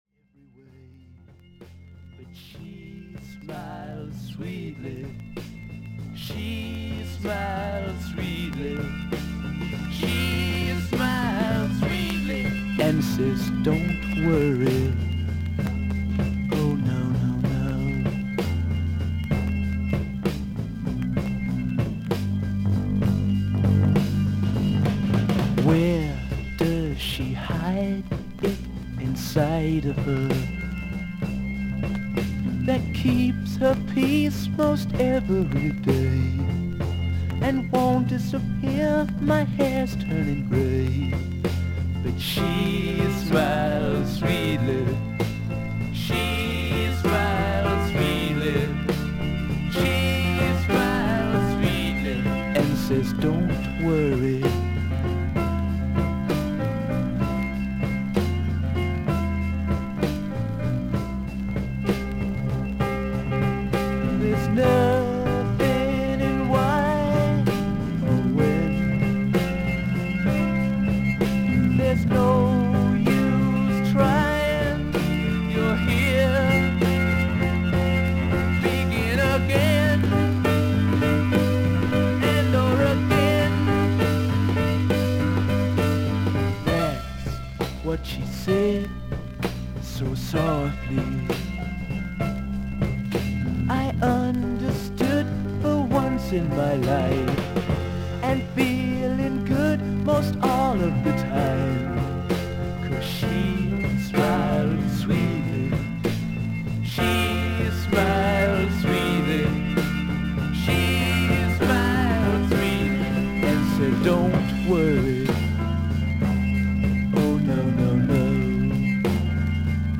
A5序盤に4mmのキズ、少々軽いノイズあり。
少々サーフィス・ノイズあり。クリアな音です。